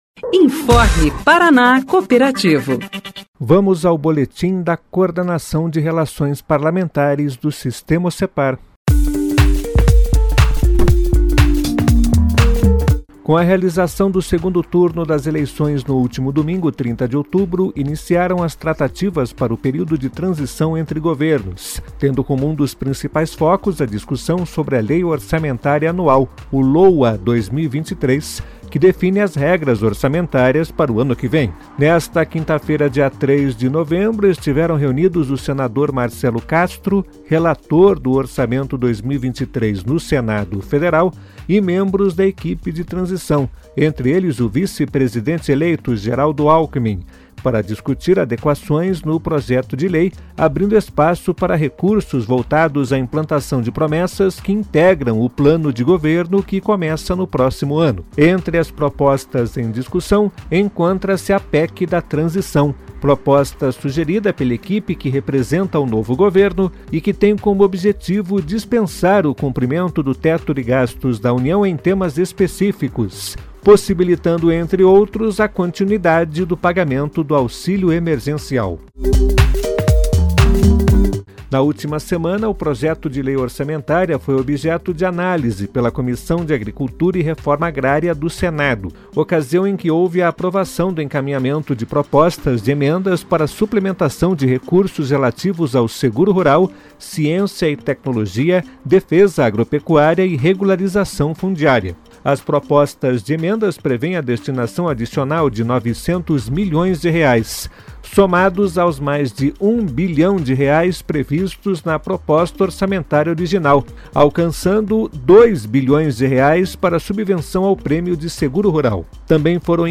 Boletim traz os temas que foram destaques na semana